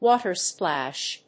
アクセントwáter‐splàsh